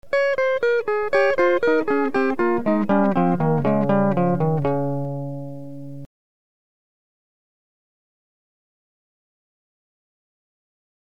The delay effect combines the unprocessed signal with a delayed copy of itself.
1 second delay
delay_1sec
delay1s.mp3